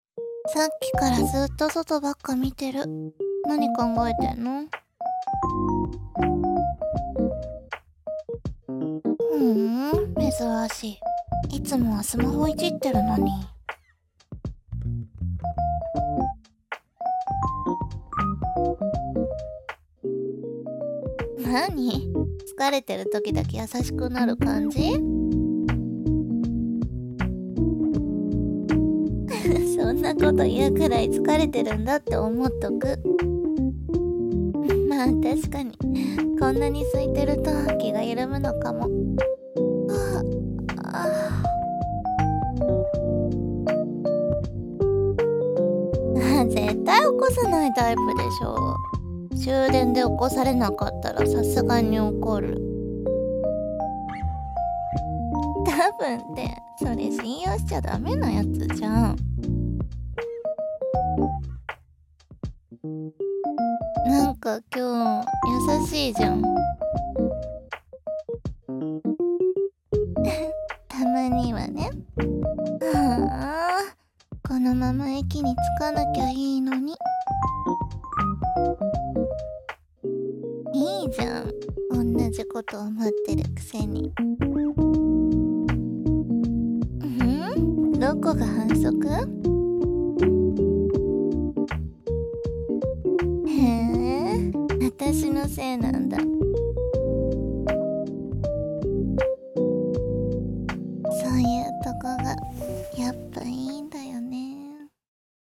【二人声劇】ひとここち